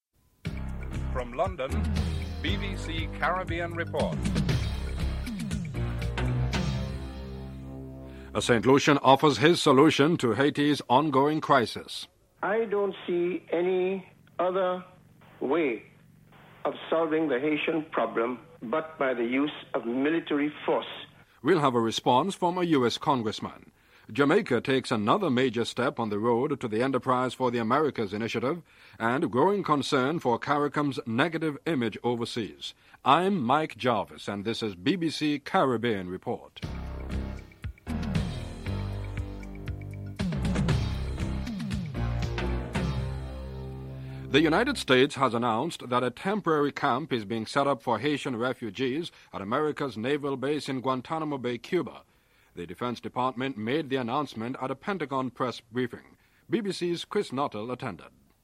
1. Headlines (00:00-00:44)
Interviews with Richard Bernal, Jamaican Ambassador to Washington and Prime Minister Michael Manley (08:31-10:56)